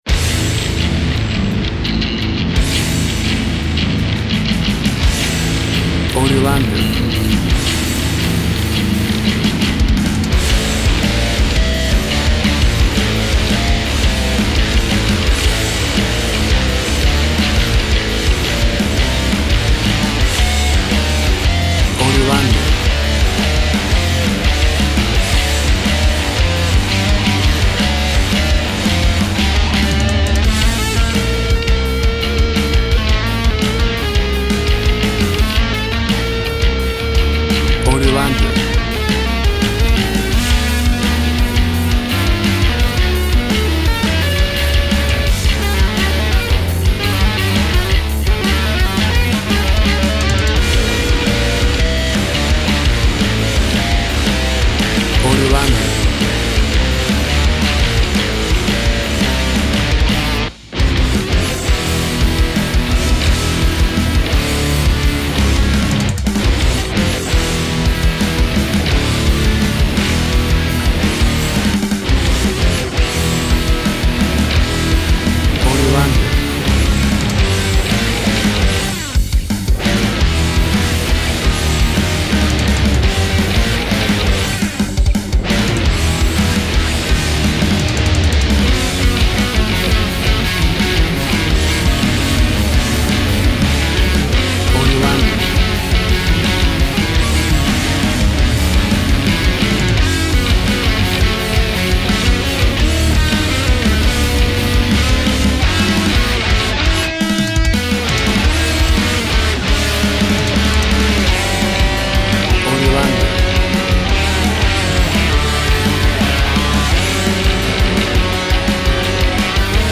Hard Metal Rock.
Tempo (BPM) 165